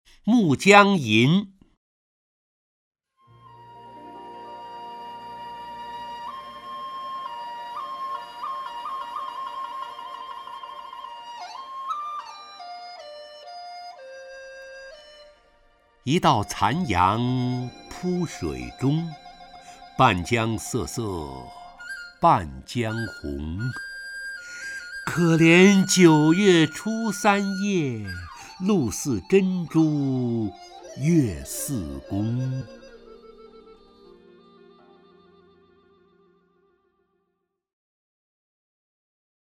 陈醇朗诵：《暮江吟》(（唐）白居易) （唐）白居易 名家朗诵欣赏陈醇 语文PLUS